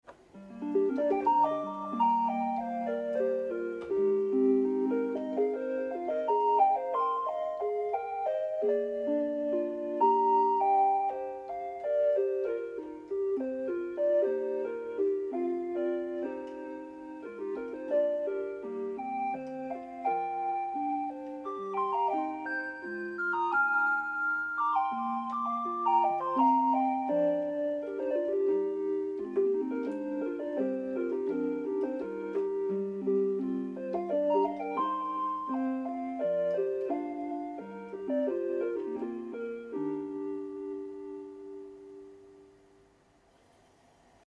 I made this patch on the Microkorg that plays a note a fifth down when you release each key and I really like the effect.